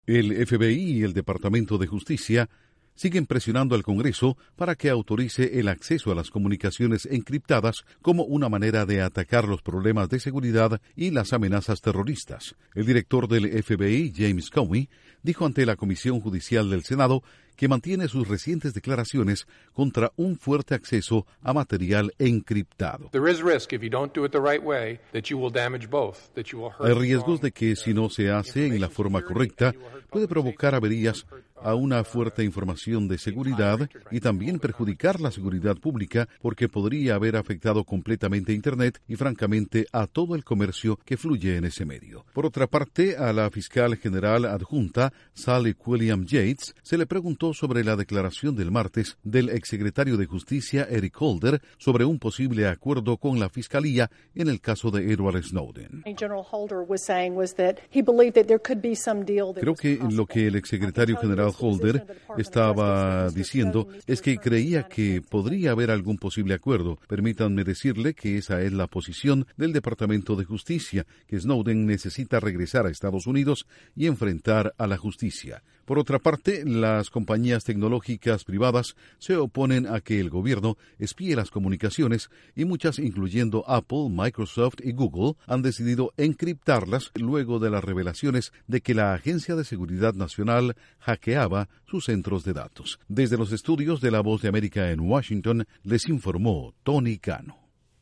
Un grupo de expertos en seguridad de Estados Unidos concluye que los gobiernos no pueden exigir acceso especial a las comunicaciones encriptadas. Informa desde la Voz de América en Washington